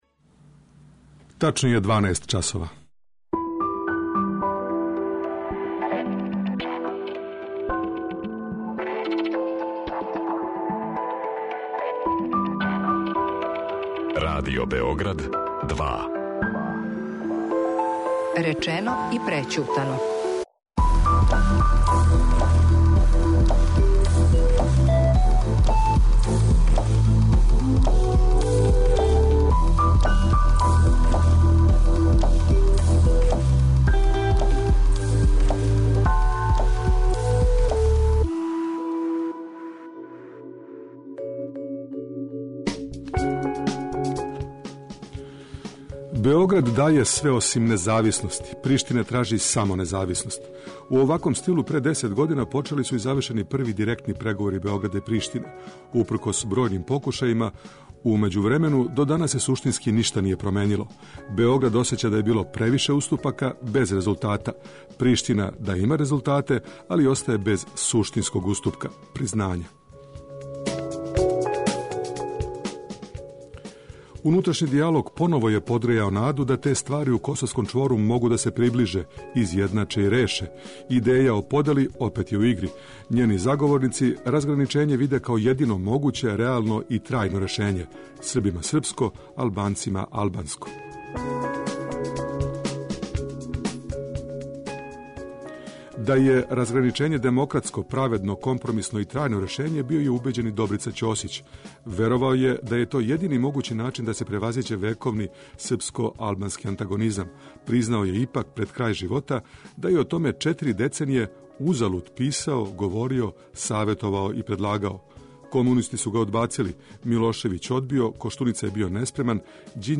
Из Приштине се јавља политички аналитичар